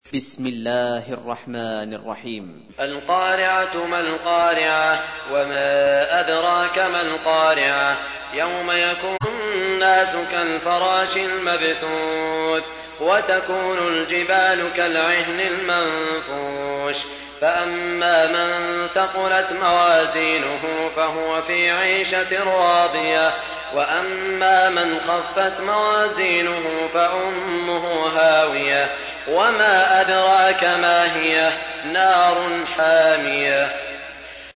تحميل : 101. سورة القارعة / القارئ سعود الشريم / القرآن الكريم / موقع يا حسين